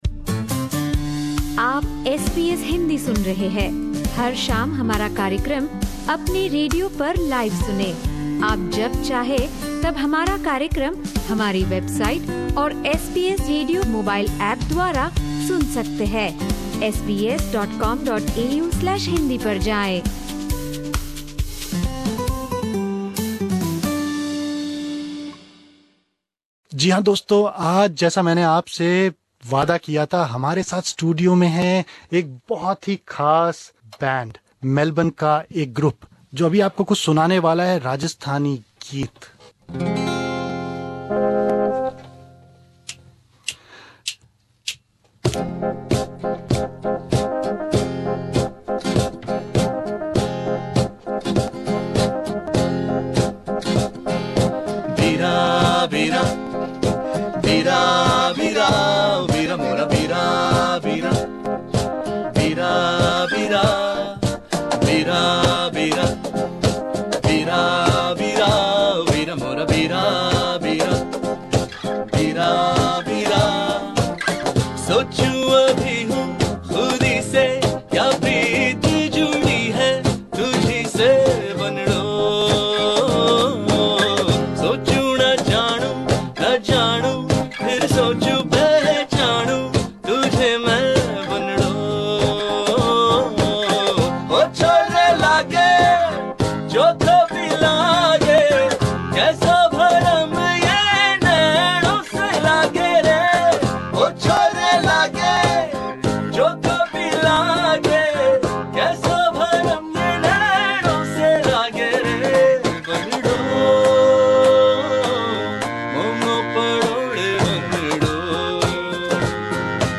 SBS Hindi’s Local Talent Series